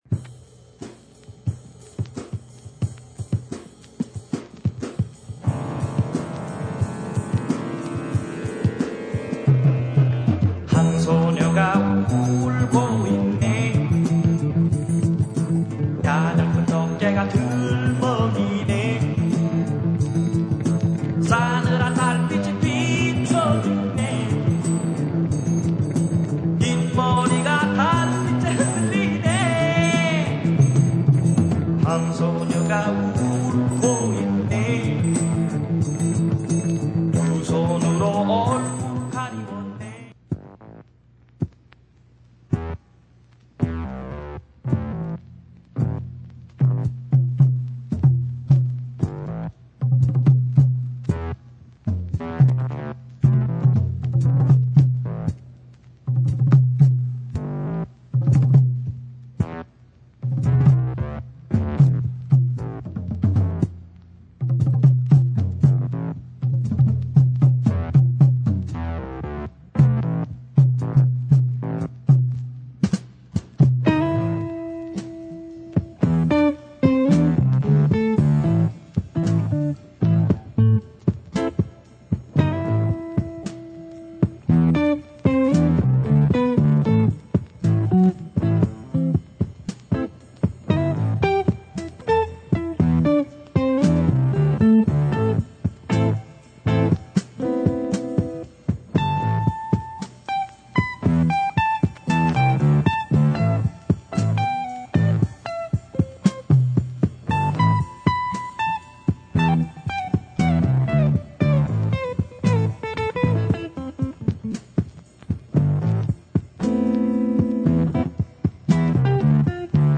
Ultra rare Korean funk score for this cult Asian movie.